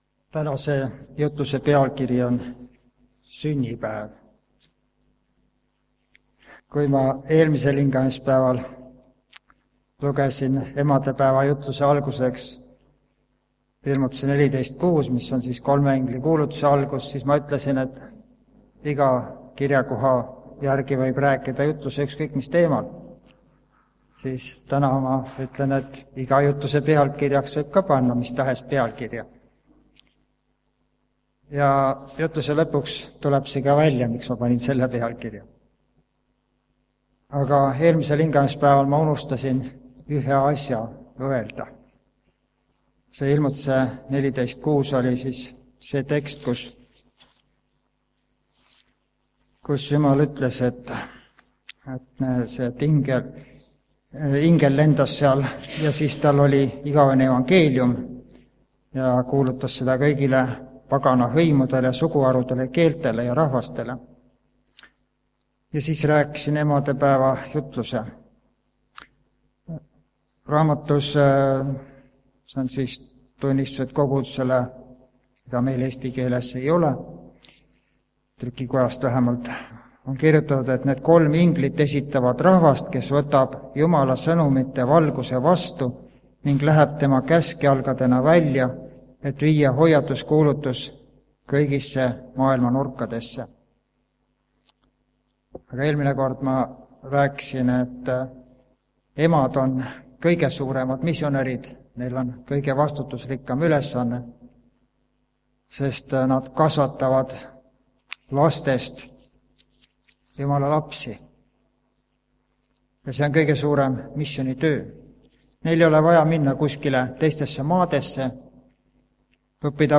Tänase jutluse teemaks on SÜNNIPÄEV